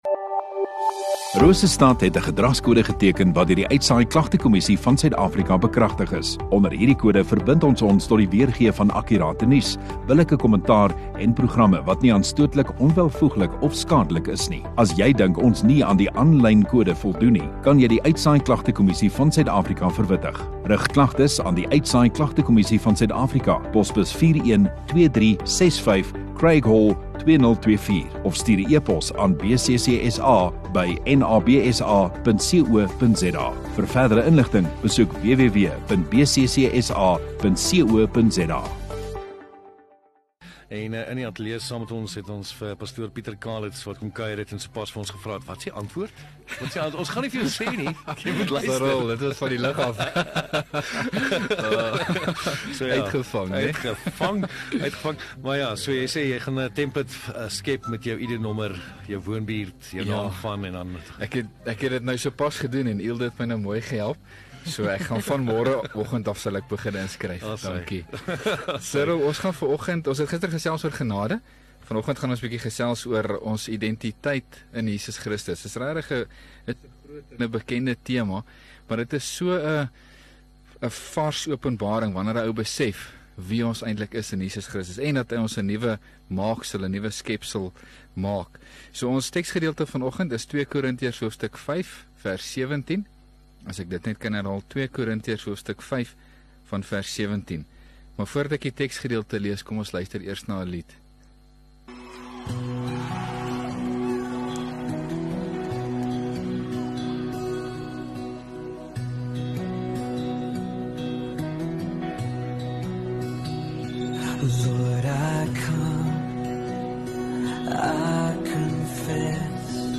Rosestad Godsdiens 5 Mar Dinsdag Oggenddiens